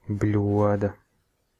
Ääntäminen
IPA : /dɪʃ/